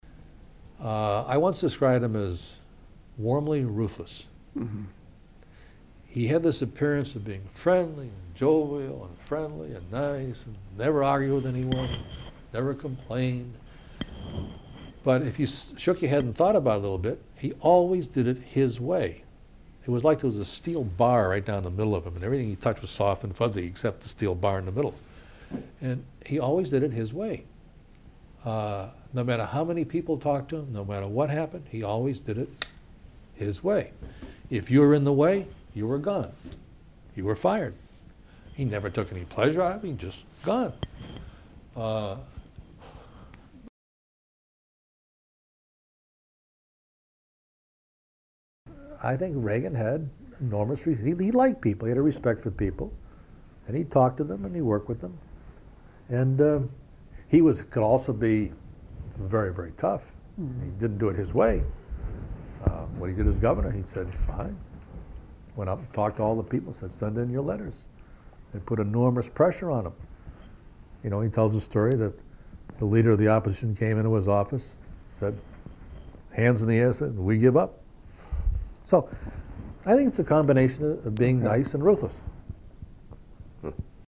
Date: December 11, 2001 Participants Martin Anderson Associated Resources Martin Anderson Oral History The Ronald Reagan Presidential Oral History Audio File Transcript